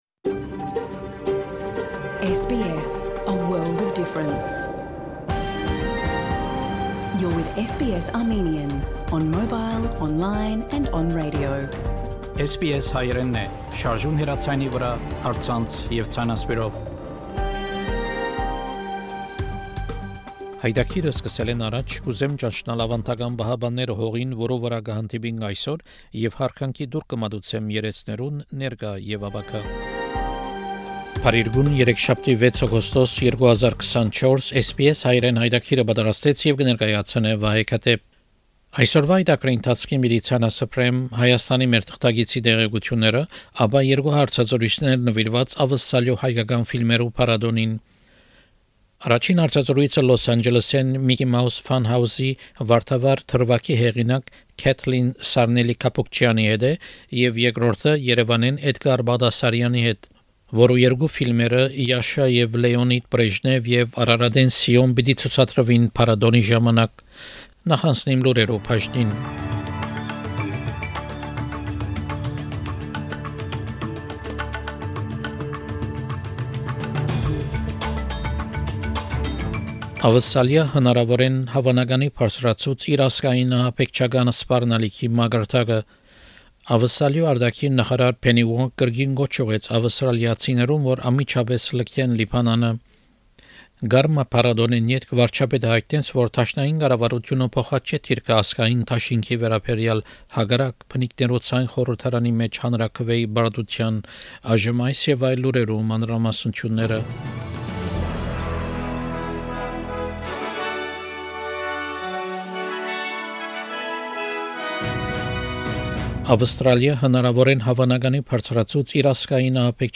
SBS Հայերէնի աւստրալիական և միջազգային լուրերը քաղուած 6 Օգոստոս, 2024 յայտագրէն: SBS Armenian news bulletin from 6 August program.